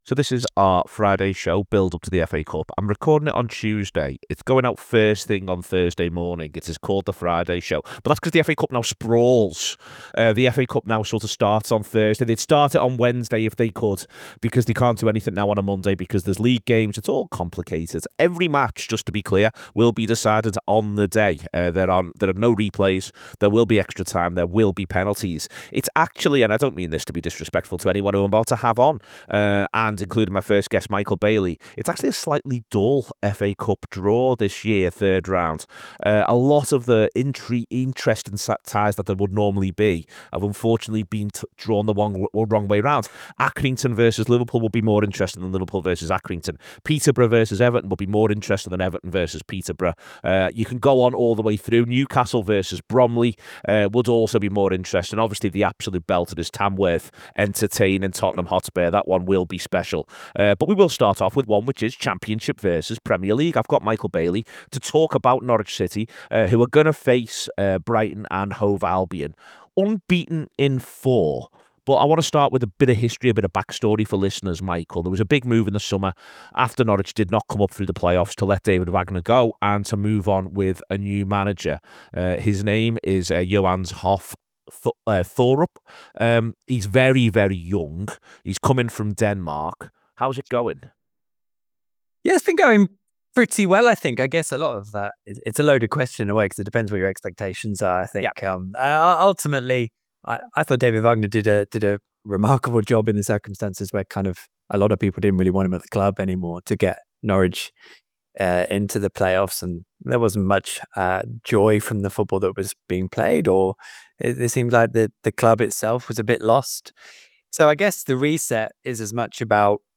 We speak to fans up and down the country ahead of a bumper weekend of FA Cup fixtures.